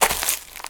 HauntedBloodlines/STEPS Leaves, Walk 24.wav at main
STEPS Leaves, Walk 24.wav